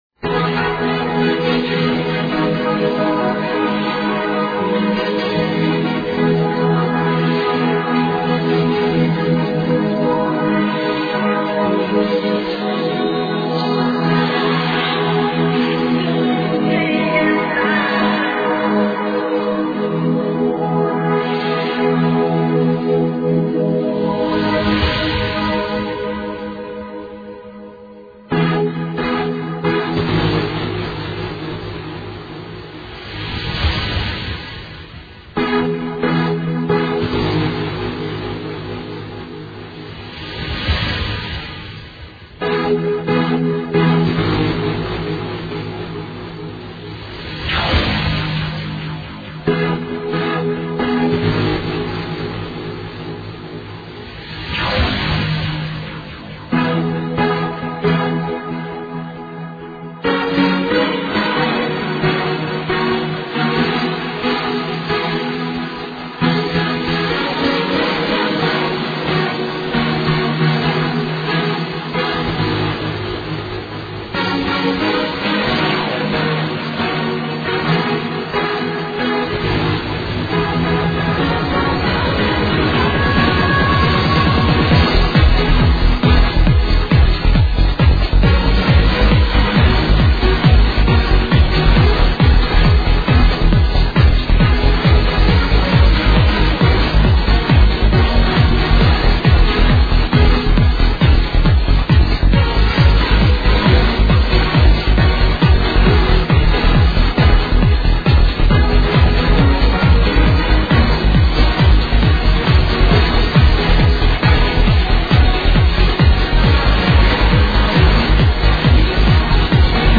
i know the beat playing i just dont remember the name